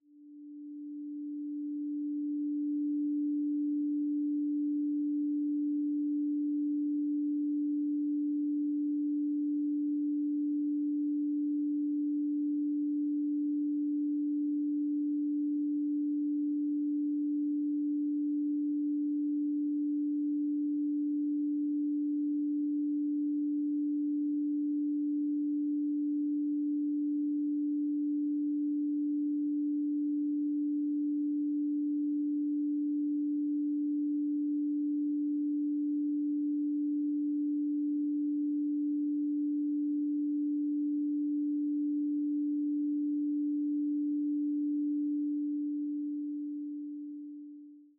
🎹 Classical Piano Collection
Beautiful piano pieces inspired by the great composers.
Duration: 0:48 · Genre: Impressionist · 128kbps MP3